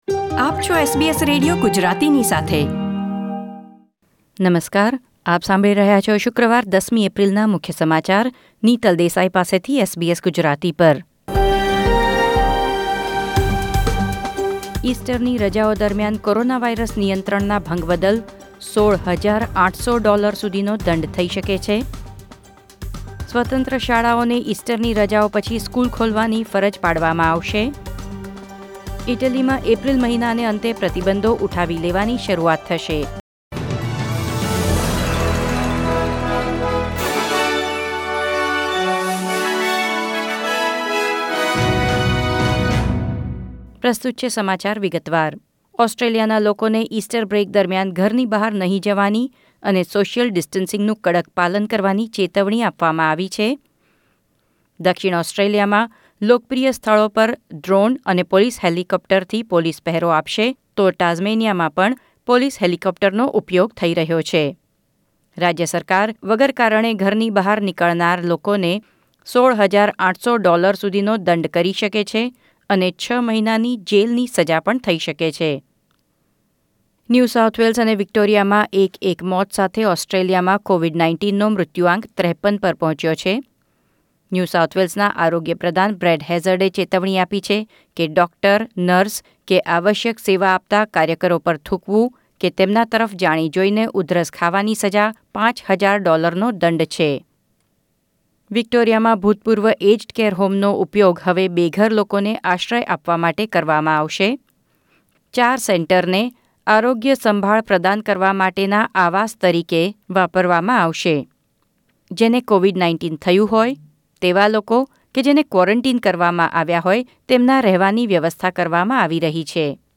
૧૦ એપ્રિલ ૨૦૨૦ના મુખ્ય સમાચાર